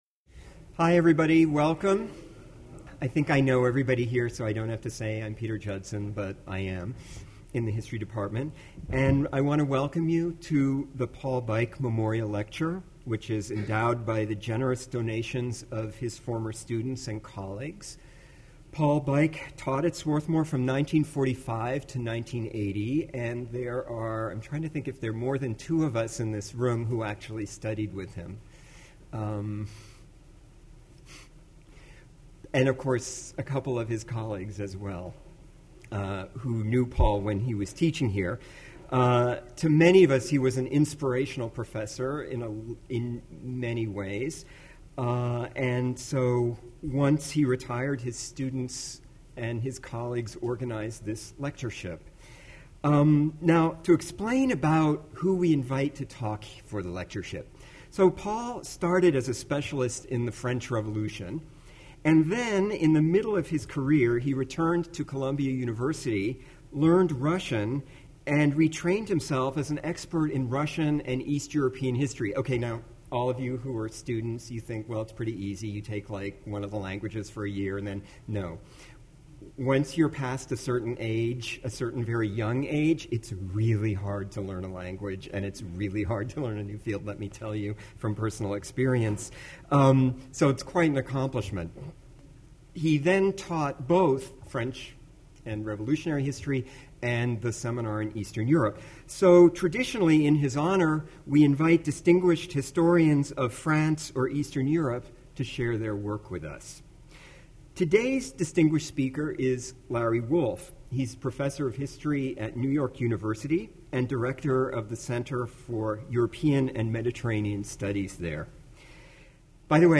The Department of History presents the 2012 Paul H. Beik Lecture